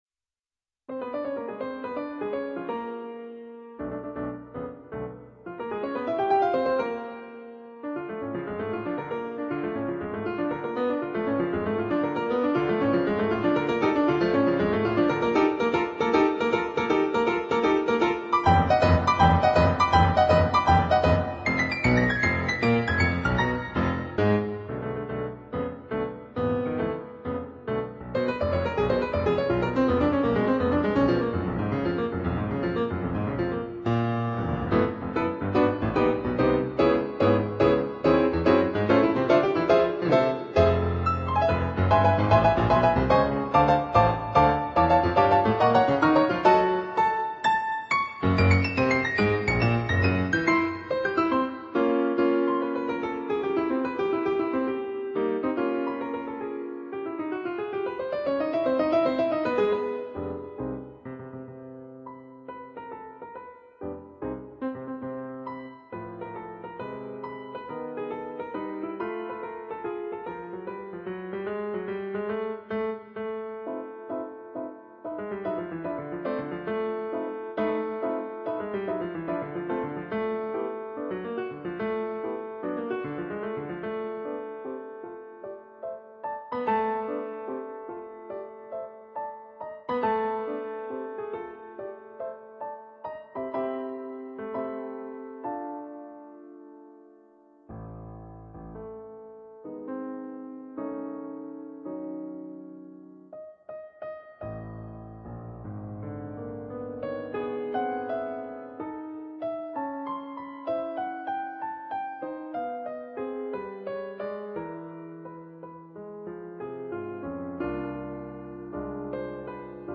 on Yamaha digital pianos.